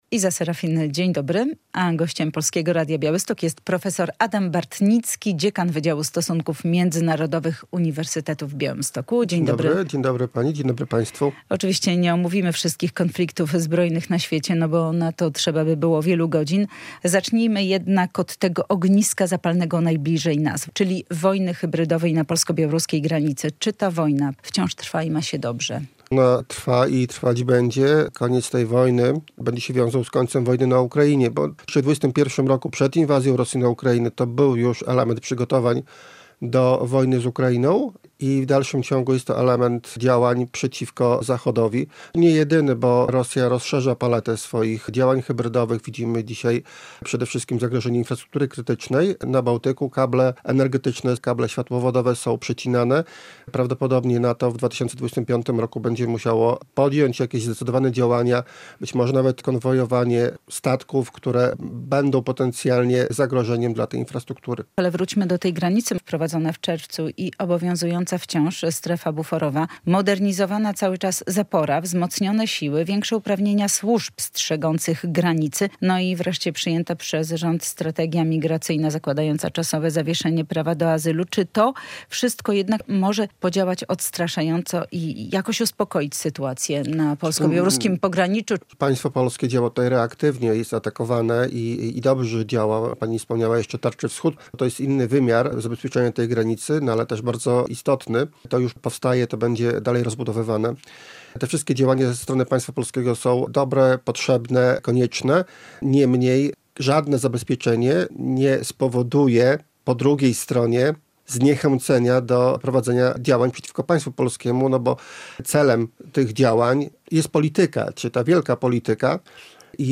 Gość
W poniedziałkowej (30.12) Rozmowie Dnia w Polskim Radiu Białystok zajmiemy się kwestią bezpieczeństwa.